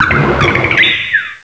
pokeemerald / sound / direct_sound_samples / cries / sigilyph.aif